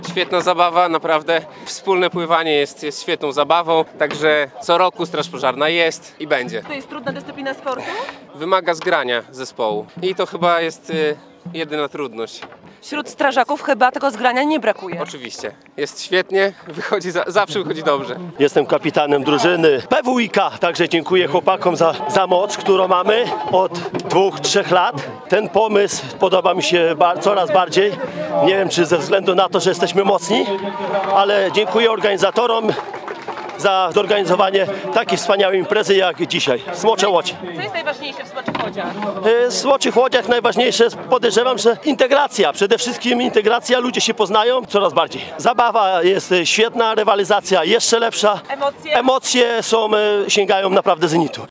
Było widowiskowo, głośno, nie brakowało również emocji.
smocze-uczestnicy.mp3